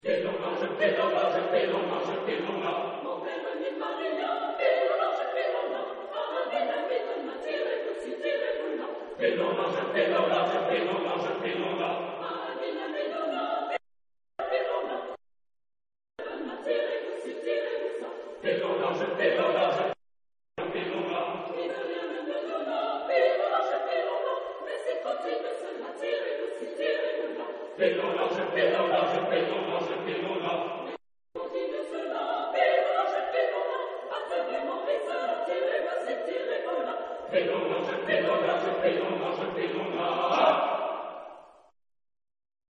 Genre-Style-Form: Secular ; Partsong ; Popular
Mood of the piece: rhythmic ; repetitive
Type of Choir: SSATB (TB à l'unisson)  (5 mixed voices )
Instrumentation: Piano (optional)
Tonality: F major